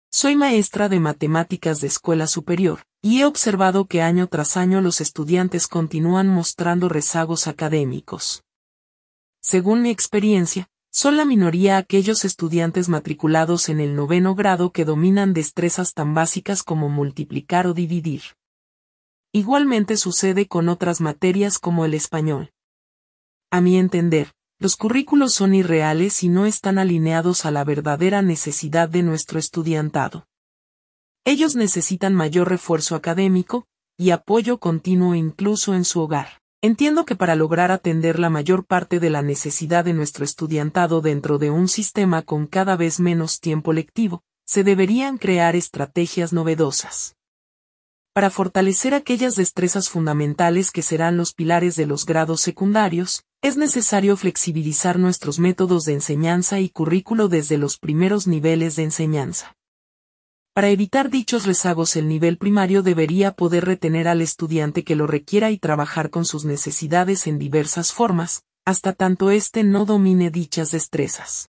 Una maestra presenta testimonio el problema de los estudiantes de escuela publica en la isla.